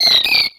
Cri d'Azurill dans Pokémon X et Y.